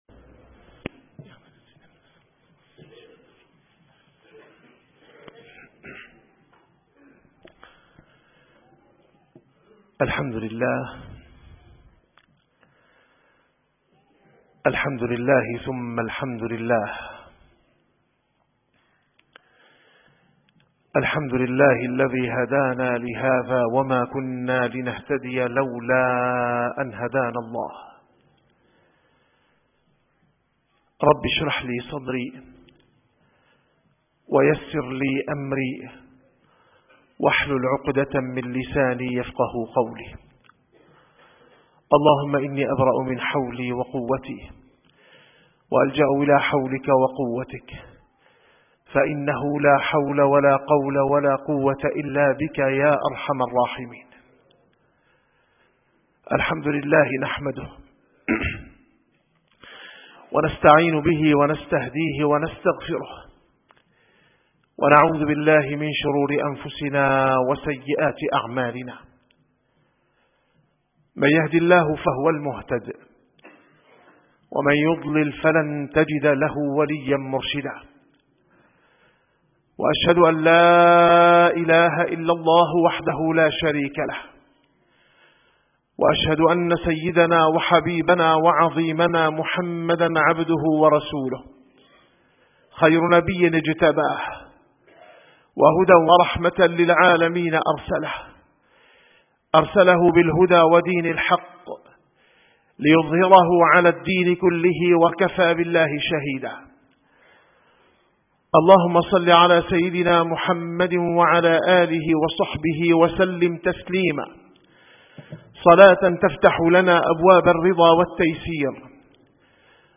- الخطب - الزهد منطلق التوازن في الحياة الدنيا